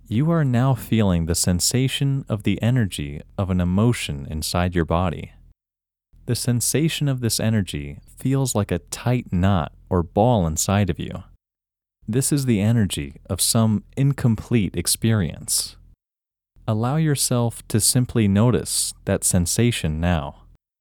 IN – First Way – English Male 2